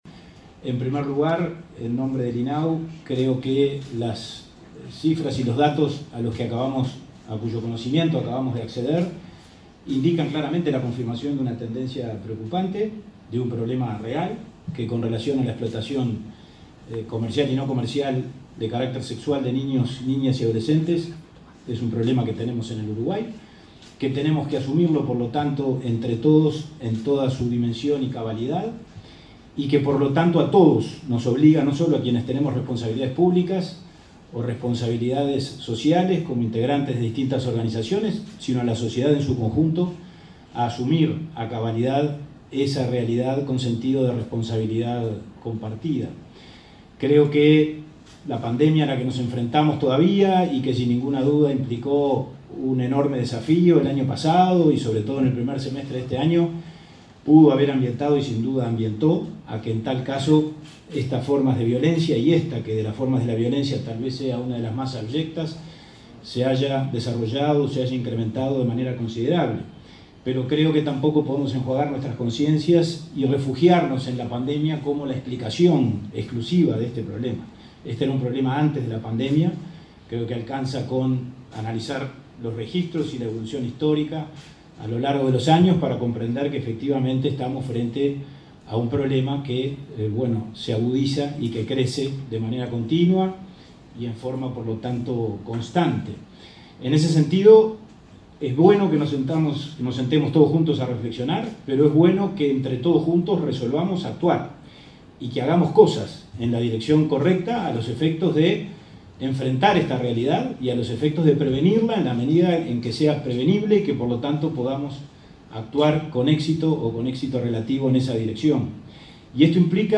Palabras del presidente de INAU y de la vicepresidenta de la República
Palabras del presidente de INAU y de la vicepresidenta de la República 07/12/2021 Compartir Facebook X Copiar enlace WhatsApp LinkedIn El presidente del Instituto del Niño y Adolescente del Uruguay (INAU), Pablo Abdala, y la vicepresidenta de la República, Beatriz Argimón, participaron este martes 7 en el Palacio Legistativo, de la conmemoración del Día Nacional de Lucha contra Explotación Sexual Comercial de Niñas, Niños y Adolescentes.